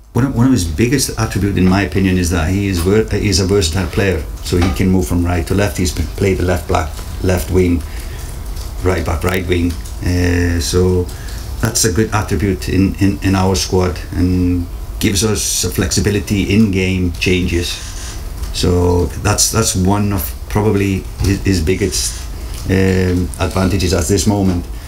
Manager Heimir Hallgrimsson says Honohan’s versatility will be important….